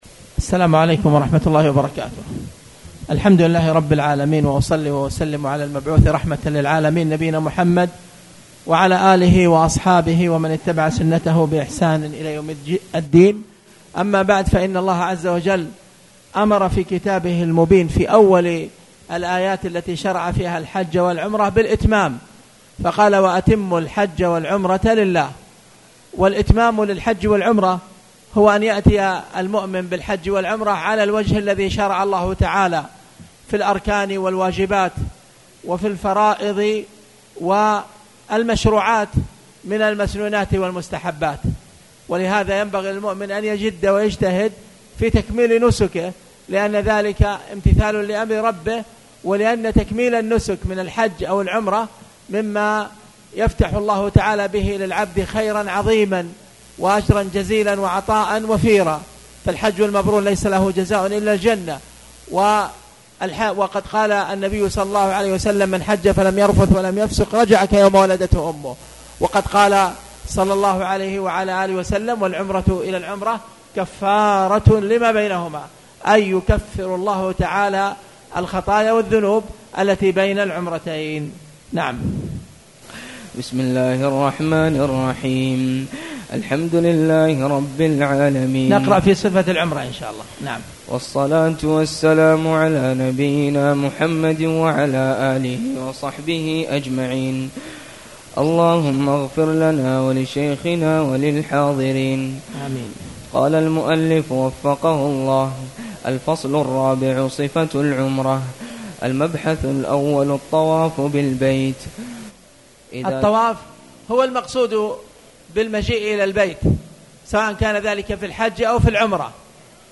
تاريخ النشر ١ ذو الحجة ١٤٣٨ هـ المكان: المسجد الحرام الشيخ